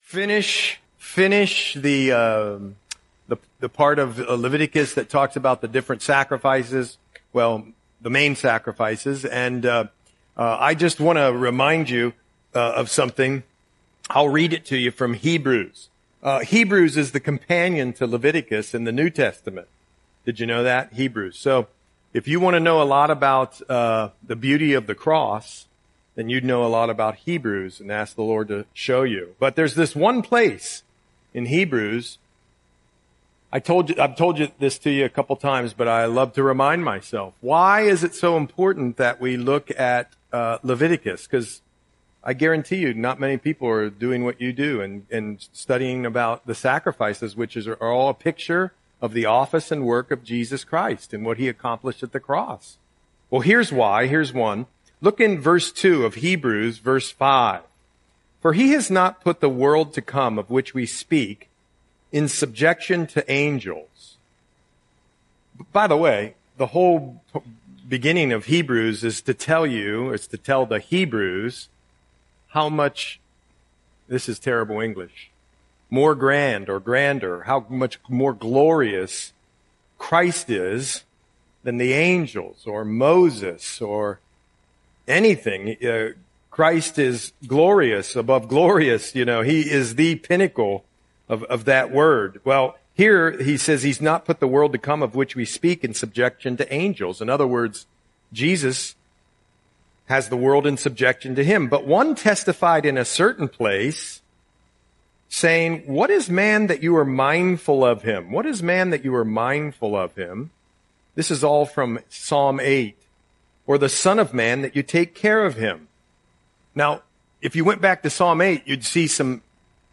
Audio Sermon - September 24, 2025